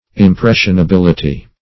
impressionability - definition of impressionability - synonyms, pronunciation, spelling from Free Dictionary
Impressionability \Im*pres`sion*a*bil"i*ty\, n.